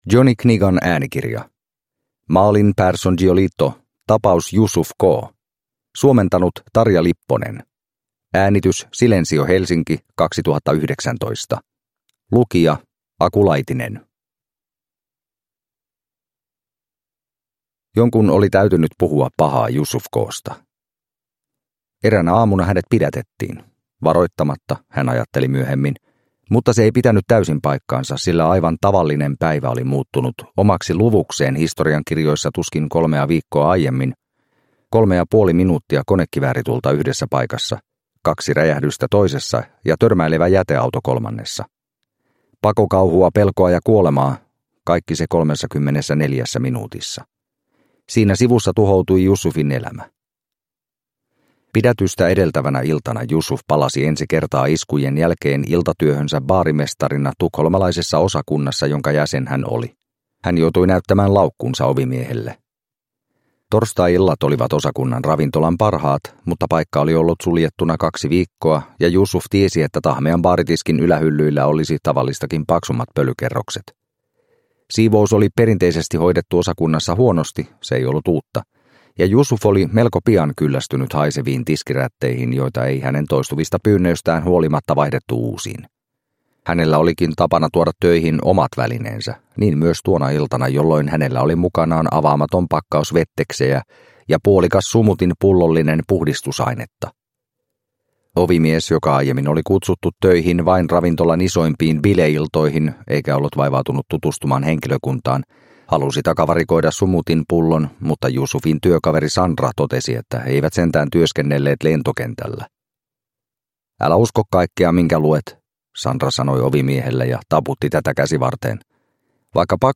Tapaus Youssuf K. – Ljudbok – Laddas ner